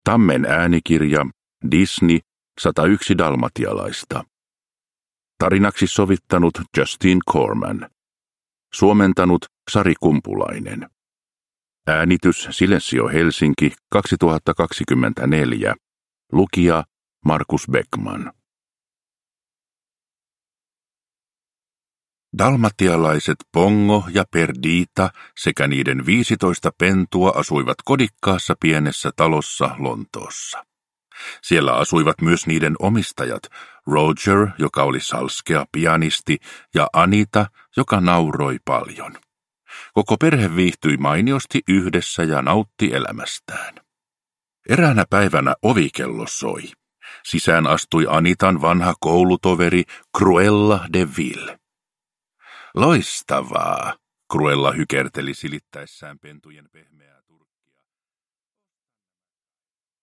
Disney. 101 dalmatialaista – Ljudbok